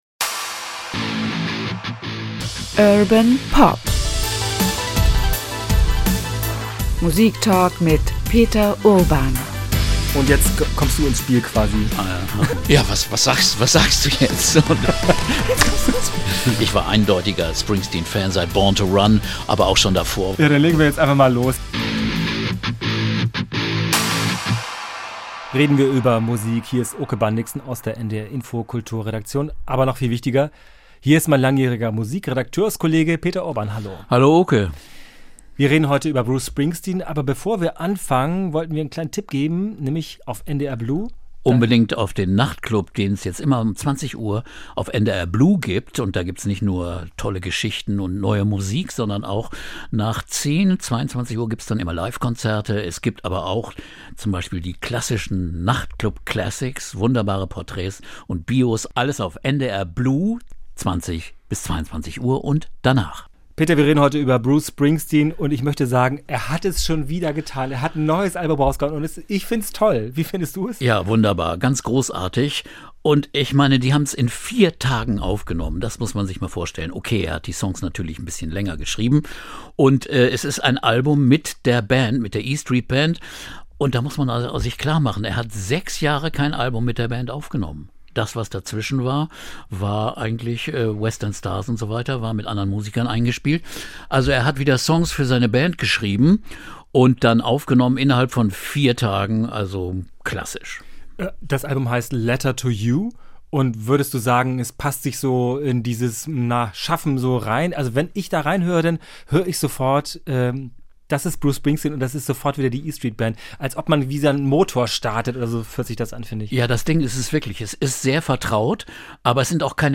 Bruce Springsteen ~ Urban Pop - Musiktalk mit Peter Urban Podcast